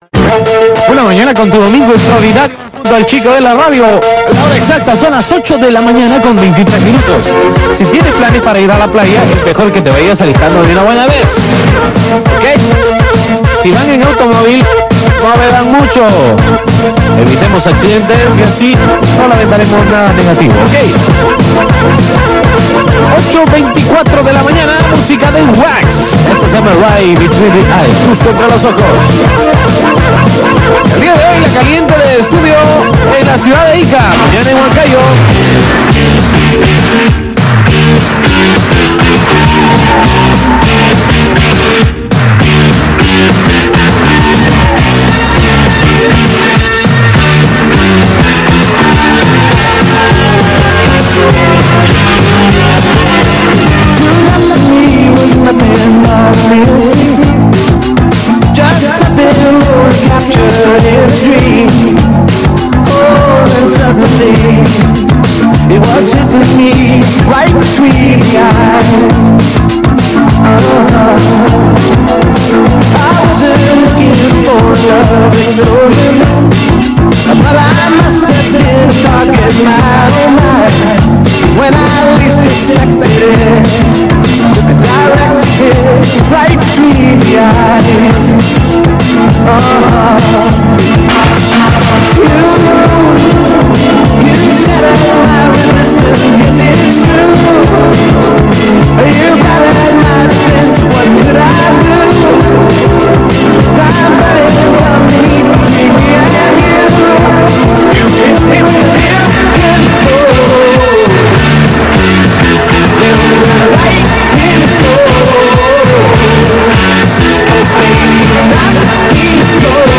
en la canción Right Between the Eyes de Wax, que se escuchaba con un tono excesivamente arriba.